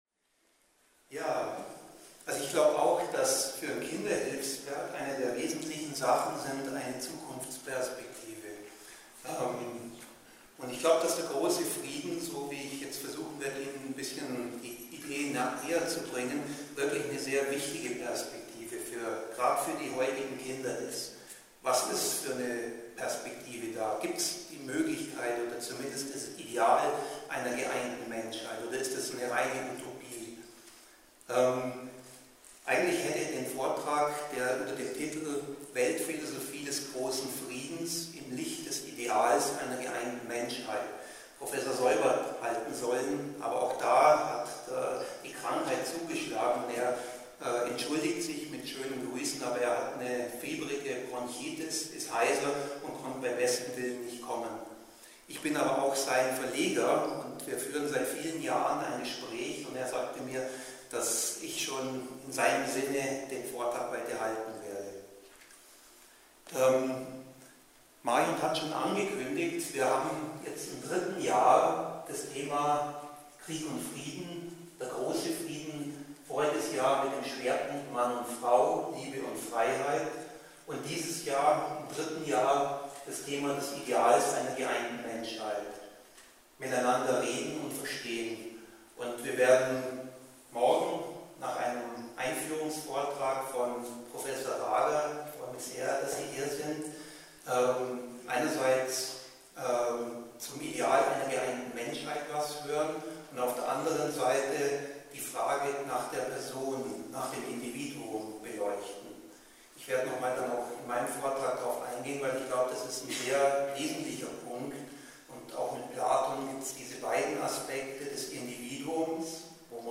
Einführungsvortag